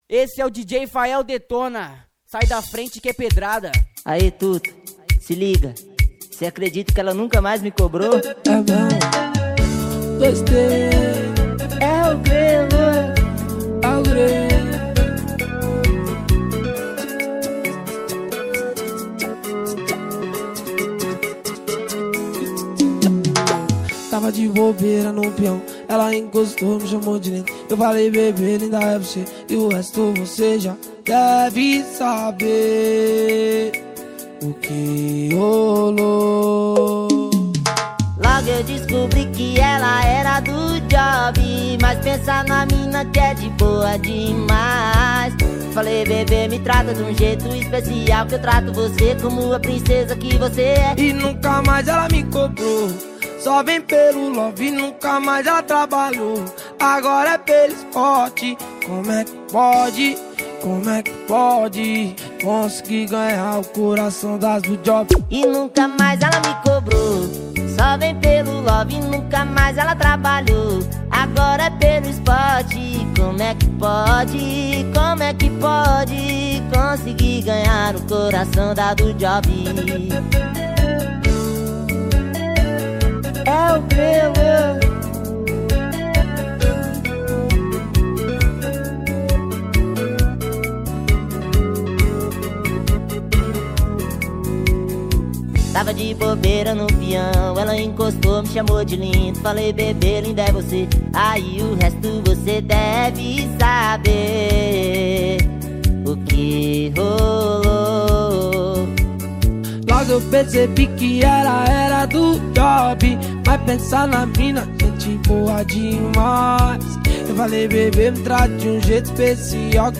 2024-12-23 00:42:35 Gênero: MPB Views